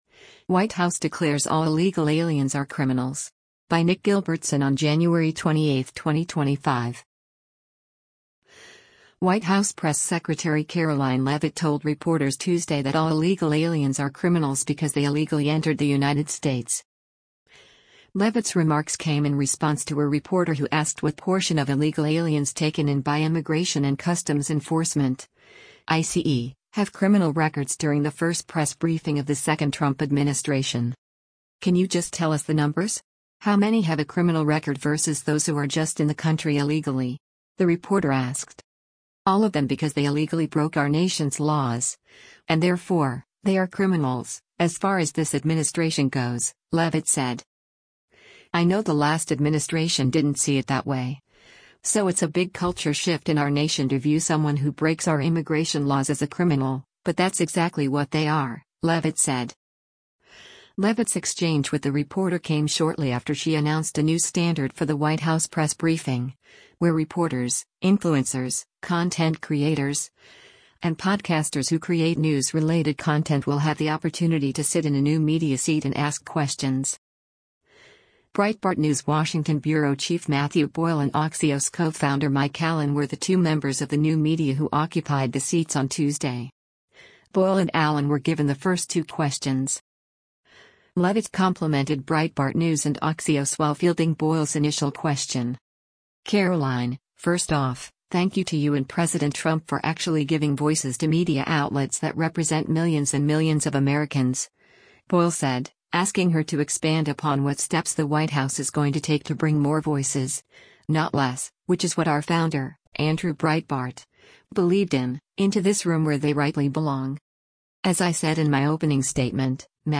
White House press secretary Karoline Leavitt told reporters Tuesday that all illegal aliens are criminals because they illegally entered the United States.